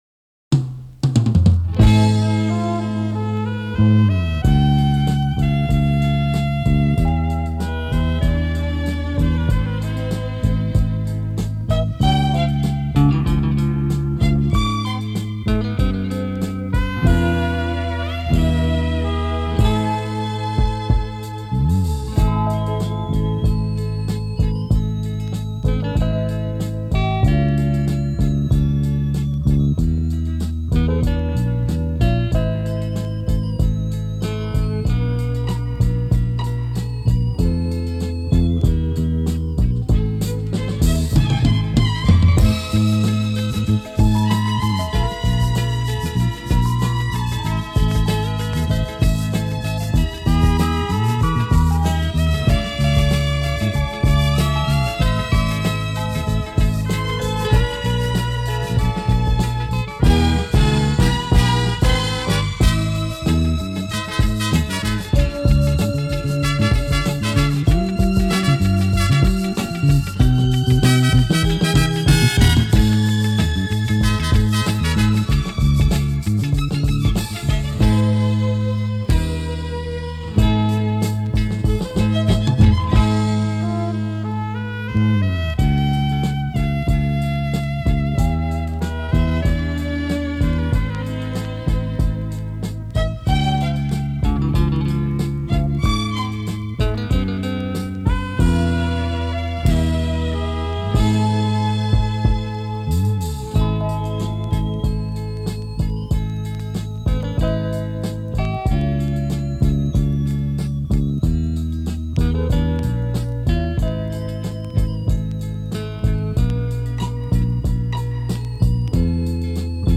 Даже по звучанию слышно - примитивная японская музычка ...